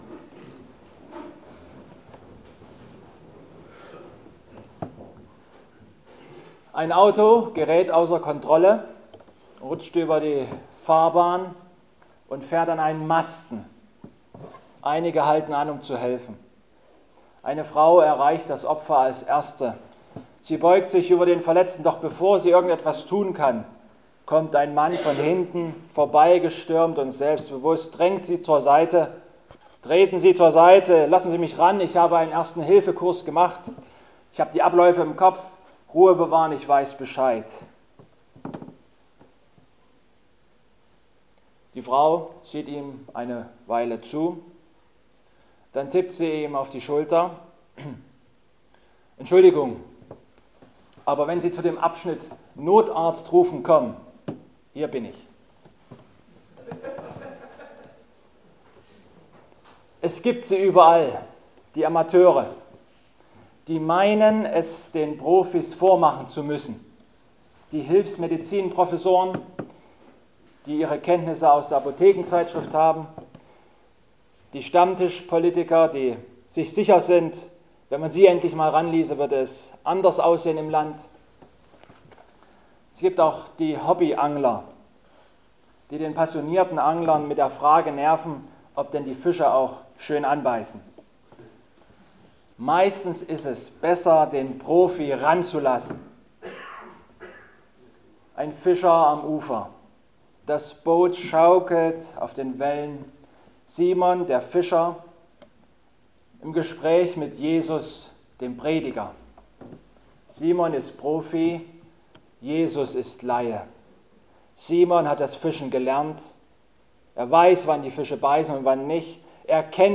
Passage: Lukas 5,1-11 Gottesdienstart: Predigtgottesdienst Obercrinitz Wir sind berufen